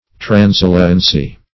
Transiliency \Tran*sil"i*en*cy\, n.
transiliency.mp3